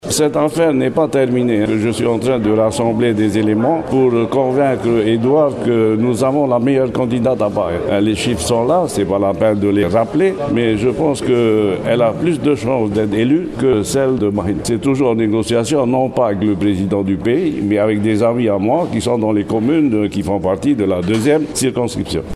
Le tavana Tapura de Paea, Jacquie Graffe, et le président Tahoeraa de l’assemblée, Marcel Tuihani, ont tenu une conférence de presse commune mercredi pour revendiquer le bon score de Nicolas Sarkozy au bureau de Punaauia/Paea samedi et pour annoncer leur soutien à François Fillon ce weekend au second tour des primaires. Jacqui Graffe a d’ailleurs saisi l’occasion pour remettre sur la table le sujet de sa candidate aux législatives…